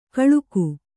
♪ kaḷuku